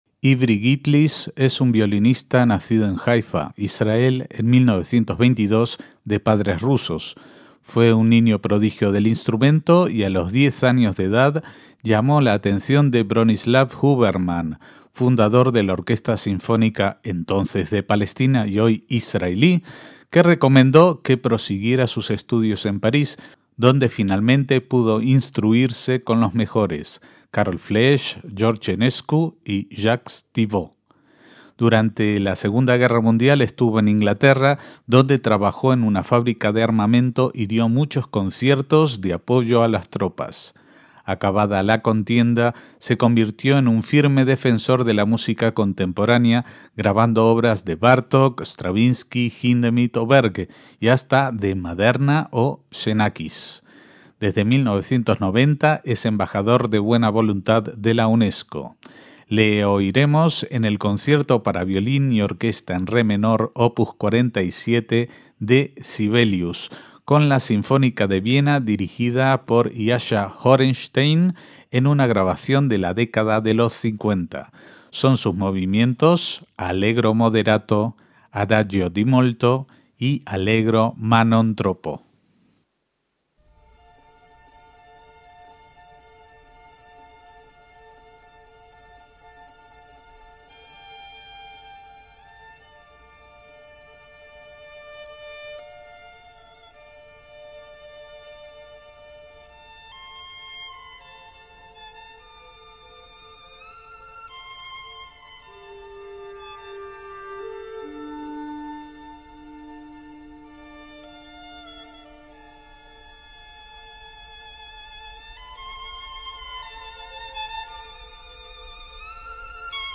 MÚSICA CLÁSICA
conciertos para violín
en re menor